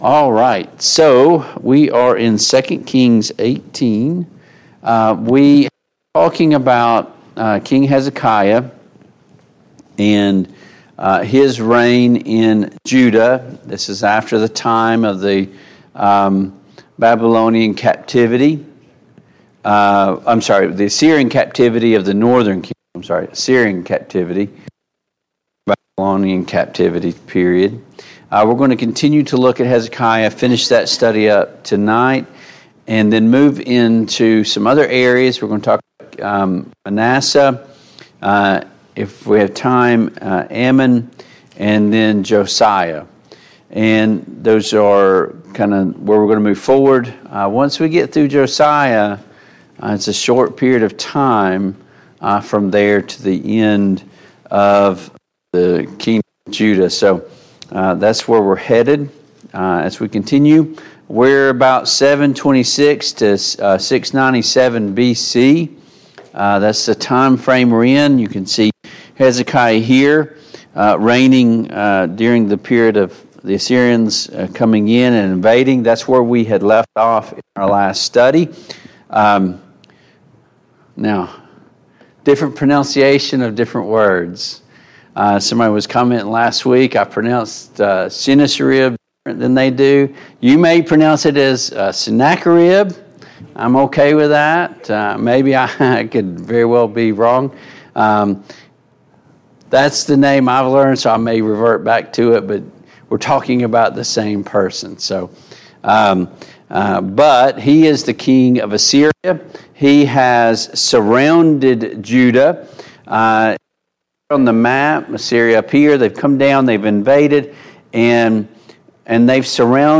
Mid-Week Bible Study Download Files Notes « 11.